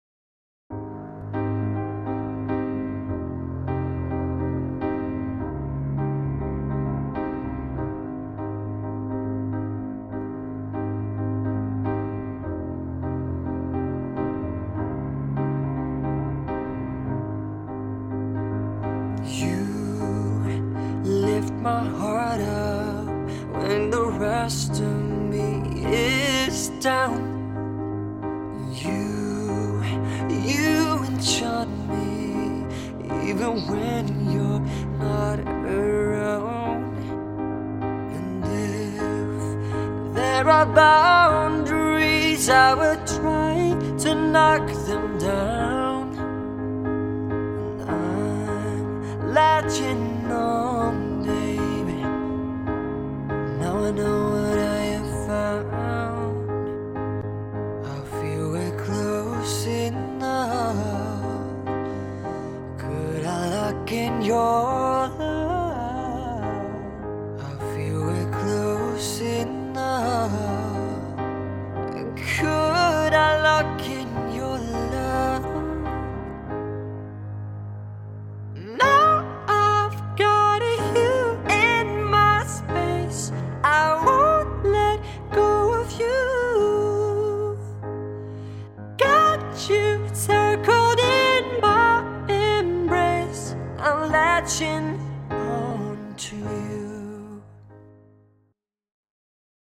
stripped down acoustic cover